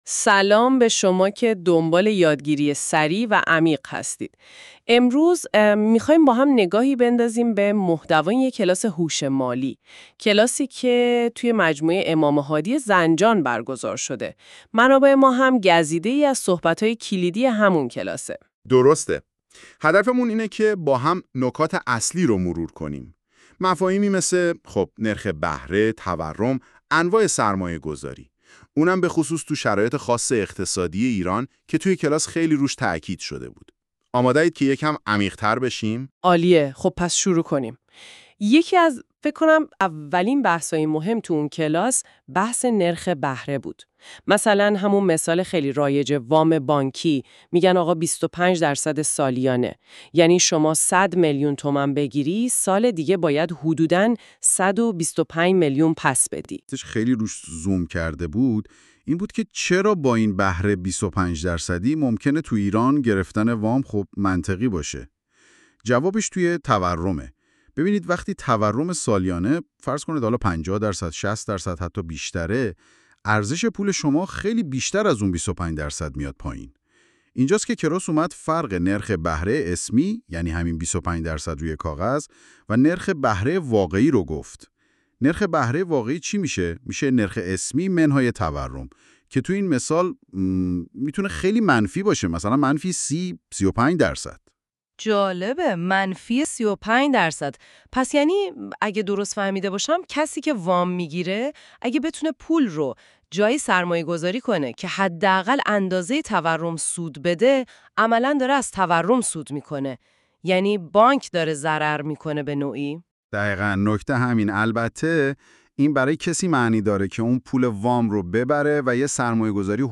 [با توجه به اینکه پادکست توسط هوش مصنوعی تولید می شود، ممکن است برخی از کلمات اشتباه تلفظ شود؛ همچنین ممکن است برخی مباحث حلقه در پادکست ذکر نشده و یا مطالبی خارج از حلقه در آن آورده شده باشد]